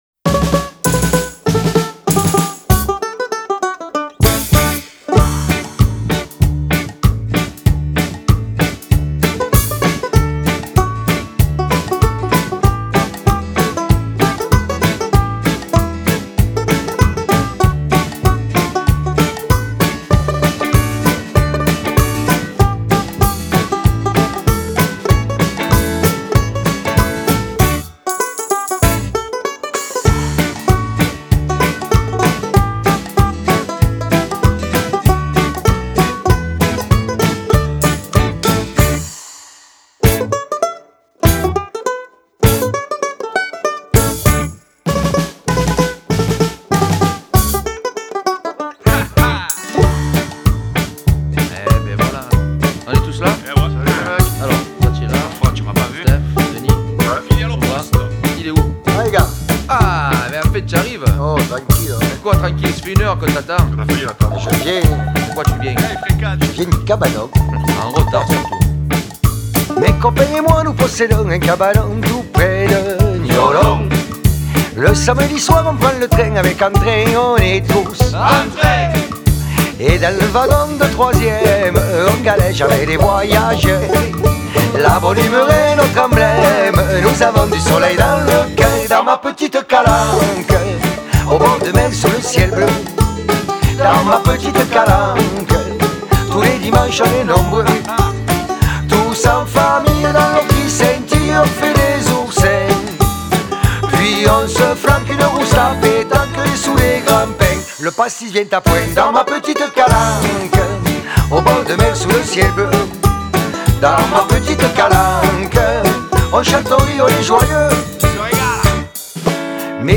version ensoleillée et toujours pleine de joie !